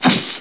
00691_Sound_poof.mp3